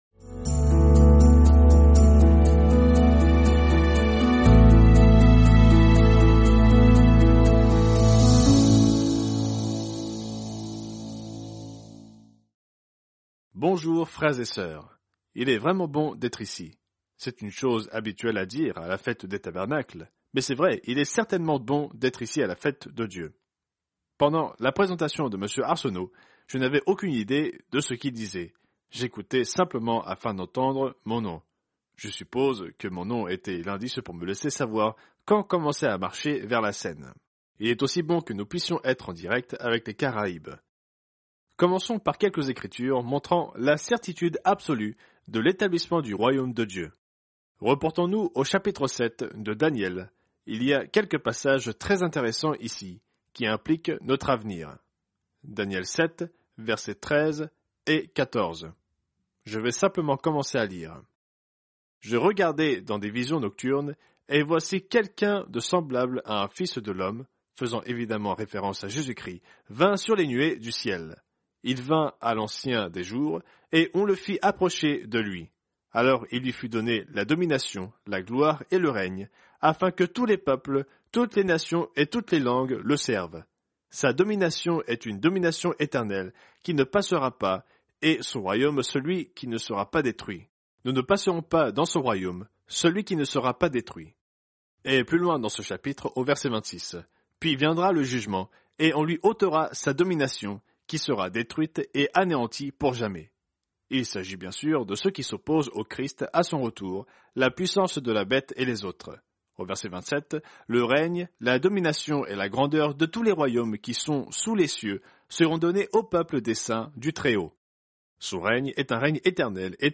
Fête des Tabernacles – 3e jour